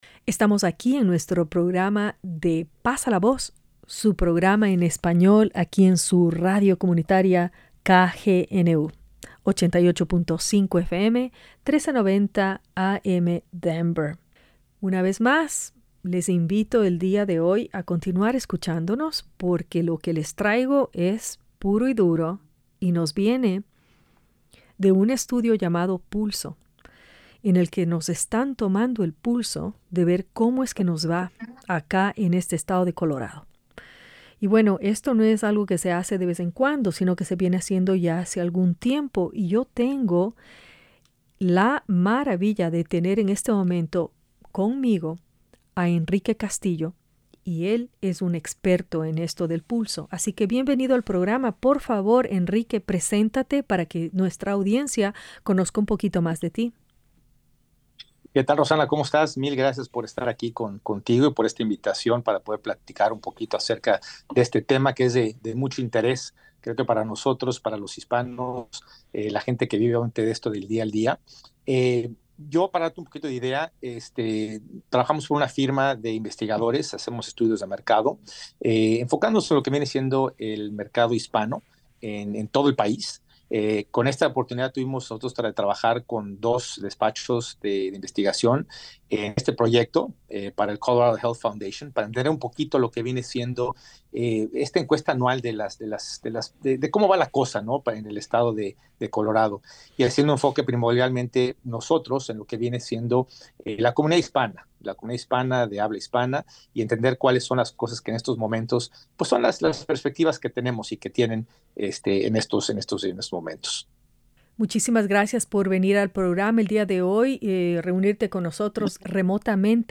Spanish Interview